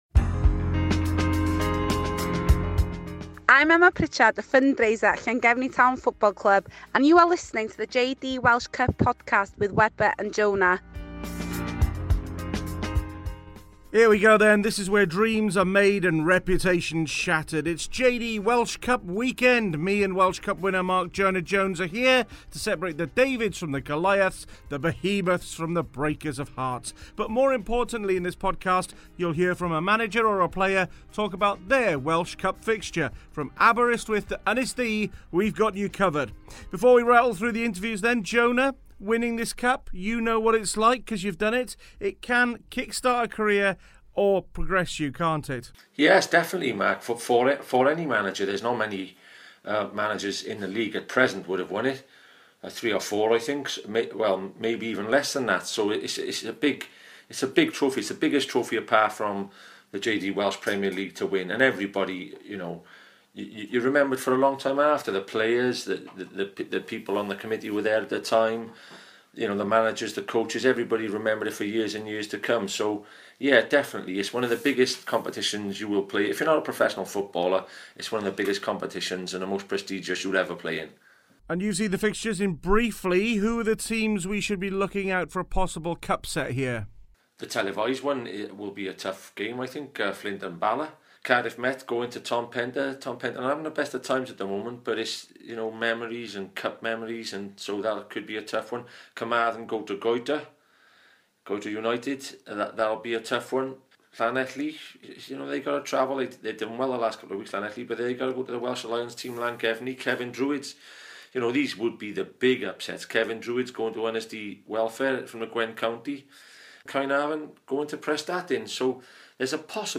Comment from managers and players at competing clubs across the land as we head into JD Welsh Cup Round Three.